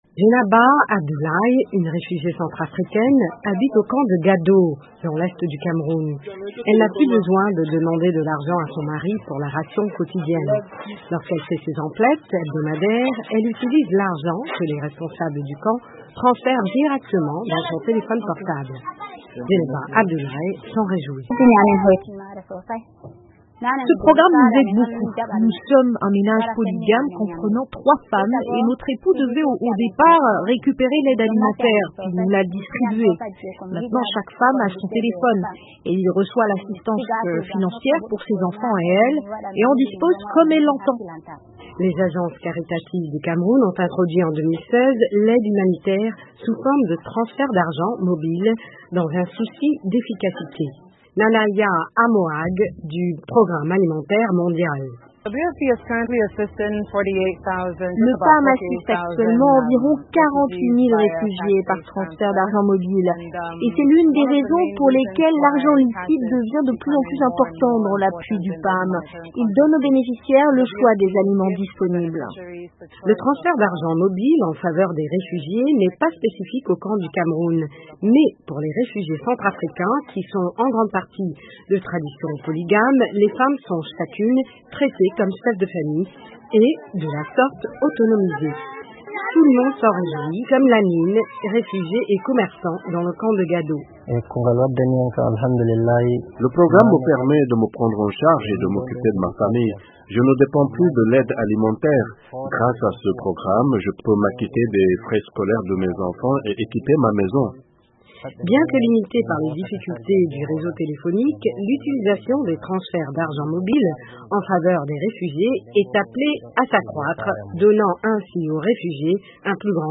Dans l’effort d’autonomisation des réfugiés du Cameroun, l’aide alimentaire a été remplacée par des transferts d’argent mobiles ; un grand avantage pour les femmes qui ne dépendent plus de leurs maris pour subvenir aux besoins de leurs enfants. Reportage